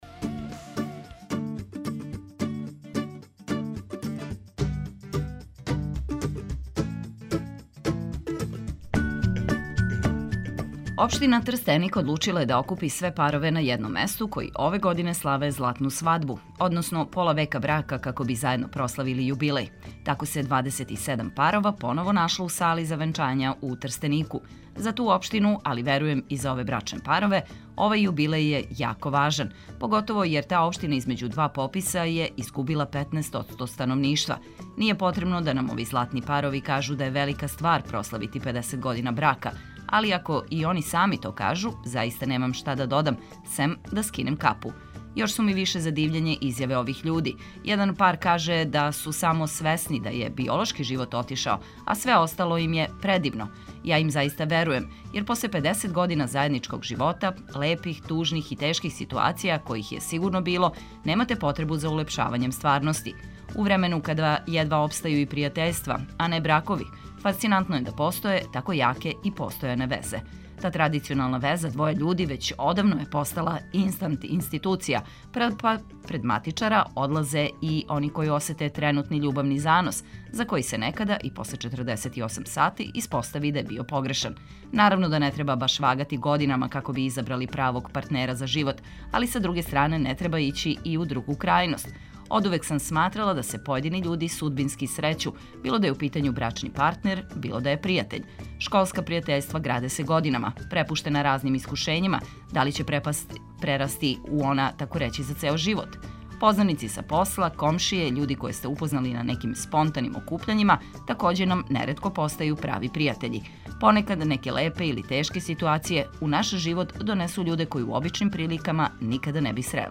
Помажемо вам да решите и неке локалне проблеме, па тако овог јутра истражујемо да ли ће житељи једног дела Видиковца добити пешачки прелаз на једној од фреквентнијих улица. И овог јутра бићемо ваше очи и уши, захваљујући нашим репортерима, али и водичи на занимљива културна дешавања.